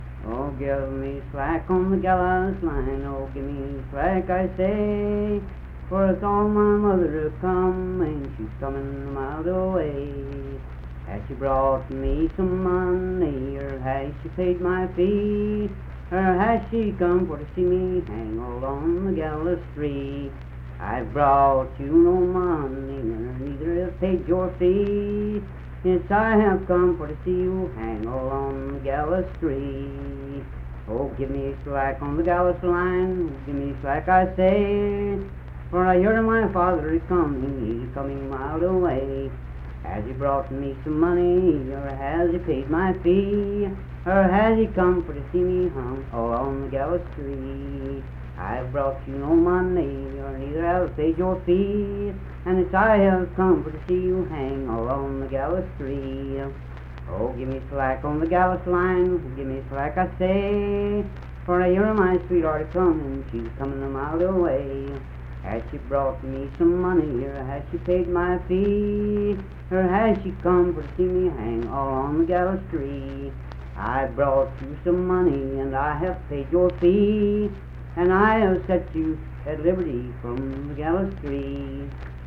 Unaccompanied vocal music
Voice (sung)
Mingo County (W. Va.), Kirk (W. Va.)